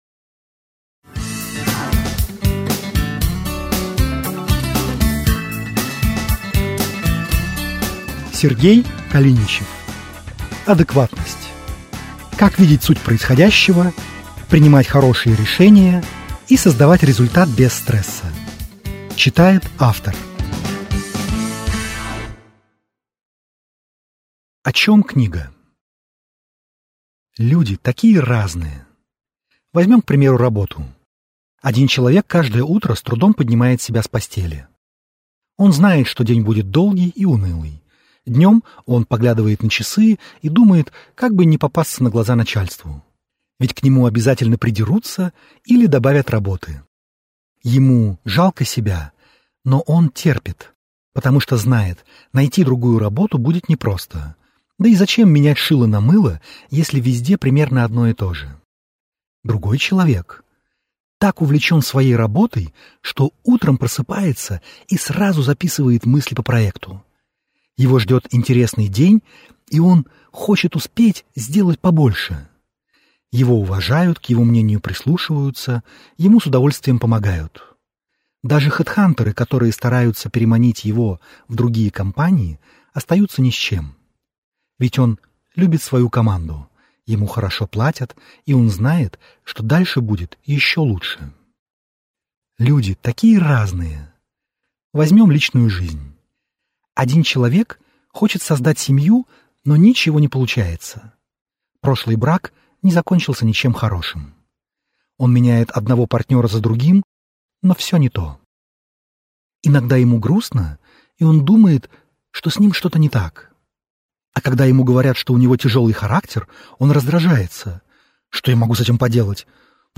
Аудиокнига Адекватность. Как видеть суть происходящего, принимать хорошие решения и создавать результат без стресса | Библиотека аудиокниг